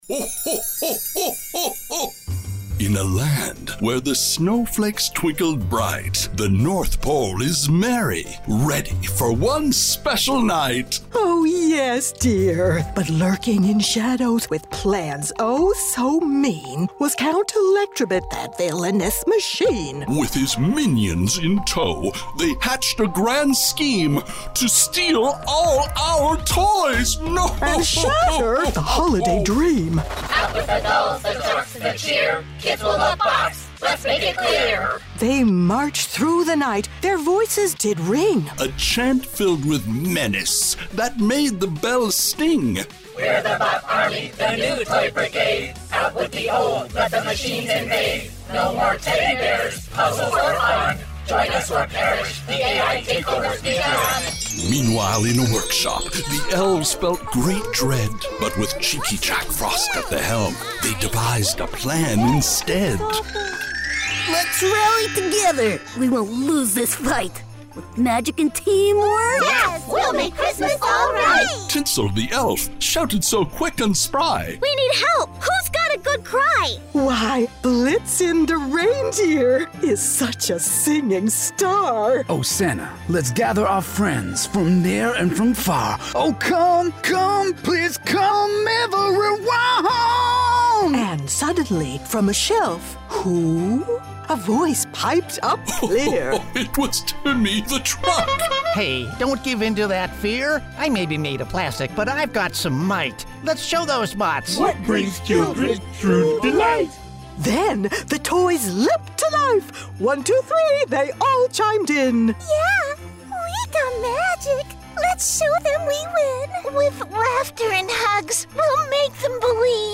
What an incredible team of supportive voice talent, donating their time and money to support those affected by the California WildFires of 2025 We put together this production to show our love and support and were able to send thousands of dollars in support